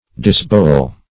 \Dis*bow"el\